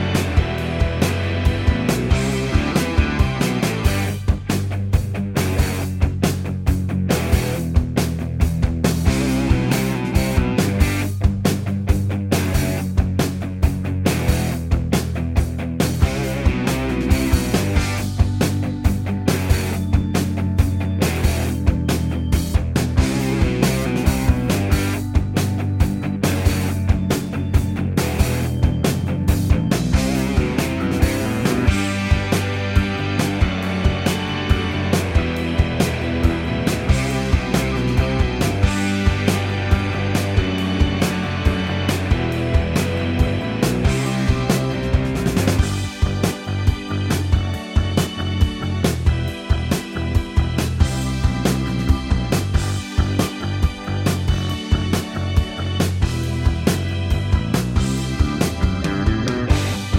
Minus Main Guitar For Guitarists 3:33 Buy £1.50